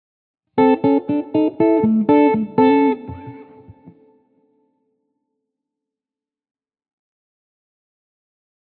HYBRID PICKING STUDIAMO QUALCHE LICK